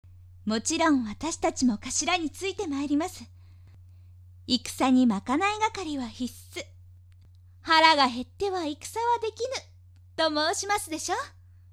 ・のんびりした優しい性格で、少し語尾が延びる。
【サンプルセリフ】
（嫌いな者を残した仲間に向かって、あくまでも優しい笑顔）